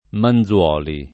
[ man zU0 li ]